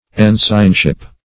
Ensignship \En"sign*ship\, n. The state or rank of an ensign.